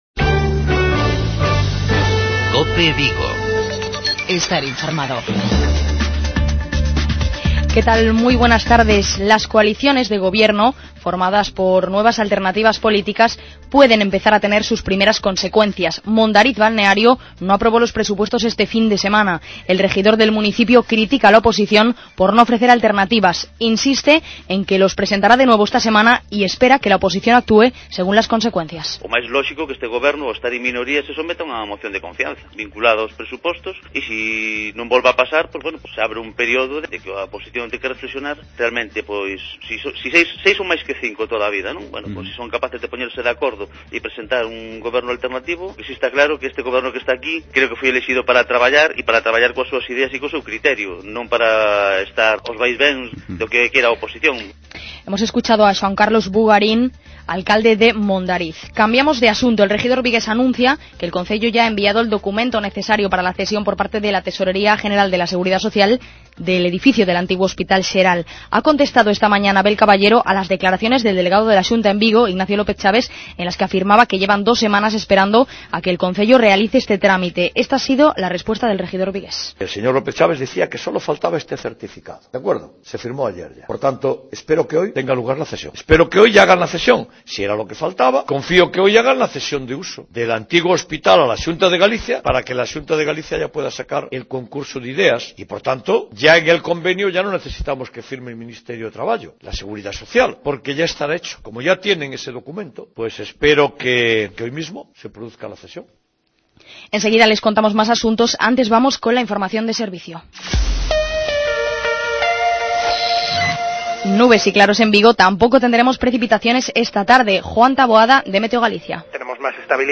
Informativos Vigo